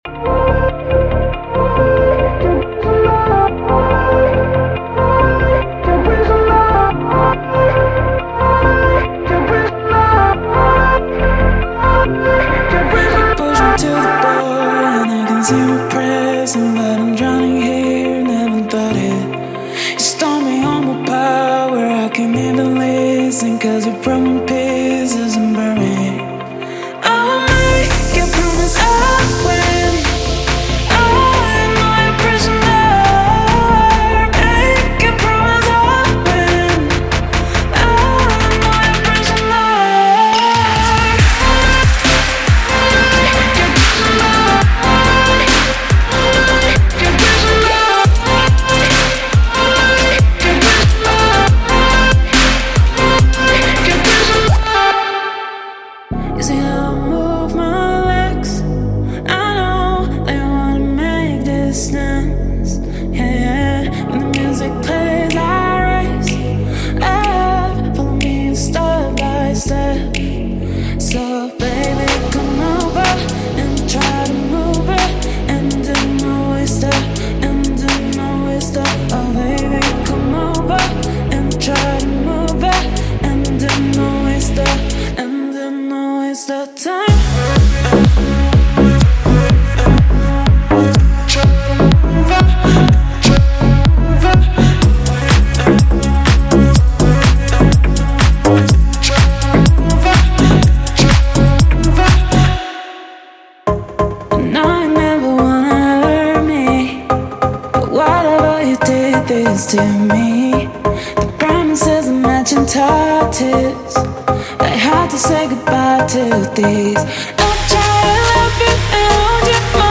此包还包含令人难以置信的人声和完美的声音，以创建您的下一个曲目。
• 14 Acapellas Wet/Dry
• 40 Drum Loops